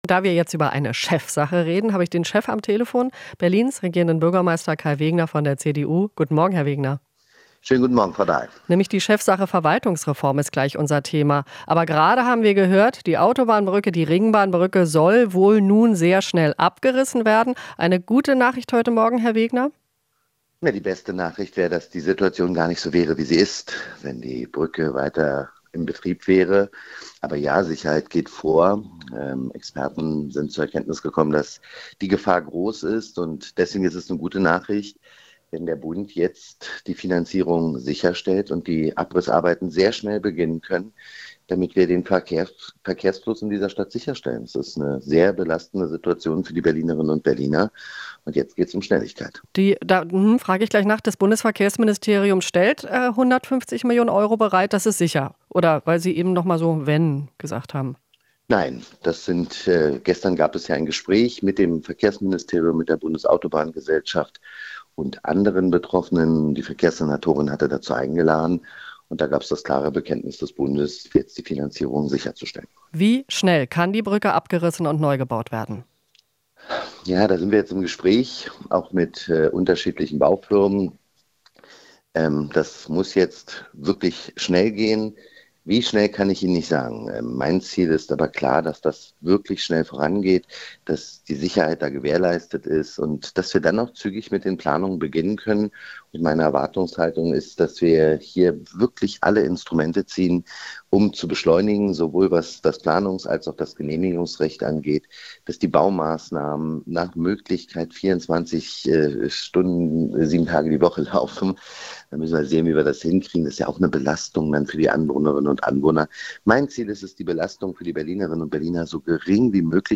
Interview - Wegner (CDU): Behördenpingpong muss enden